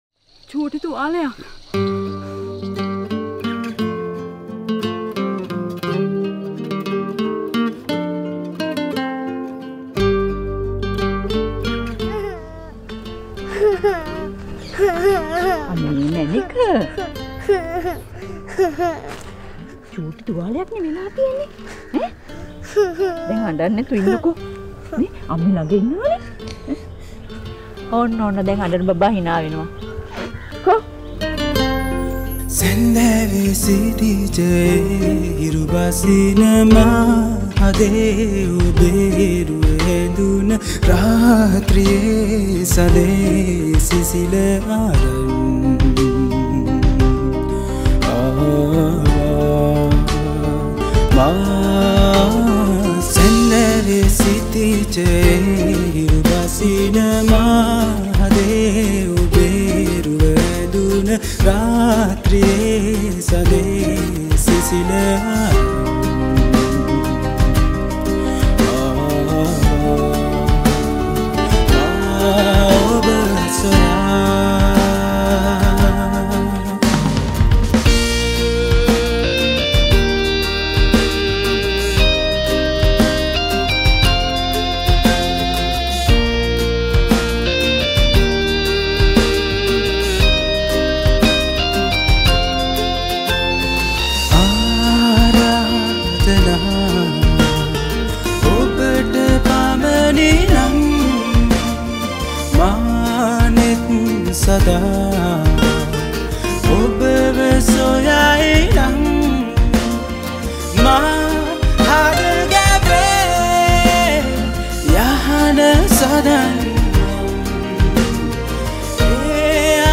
Sinhala Songs